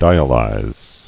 [di·a·lyze]